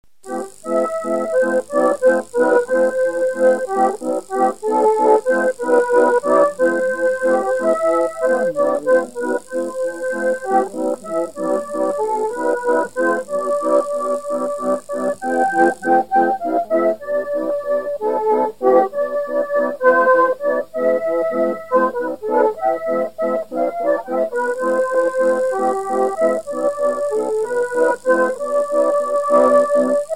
Valse
Poiré-sur-Velluire (Le)
danse : valse
Pièce musicale inédite